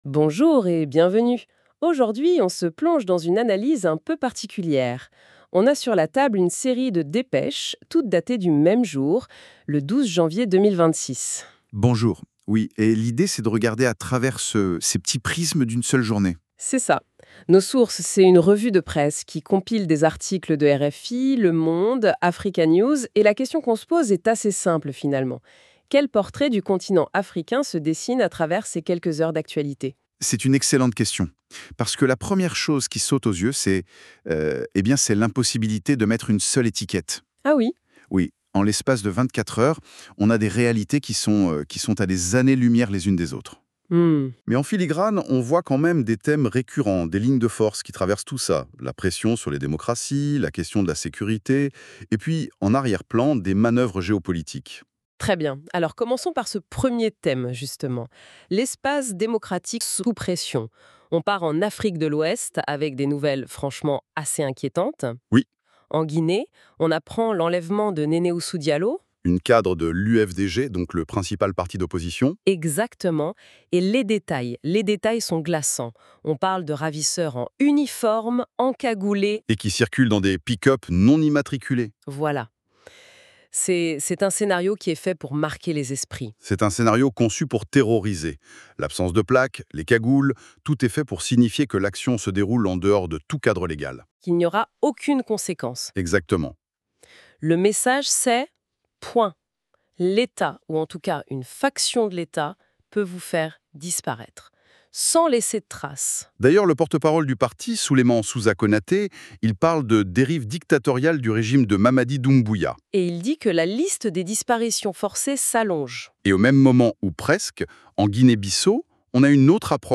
Bonjour & Bienvenue sur la Revue de presse de l’actualité africaine. Vous êtes sur RADIOTAMTAM AFRICA, la voix des peuples, en direct de Bezons.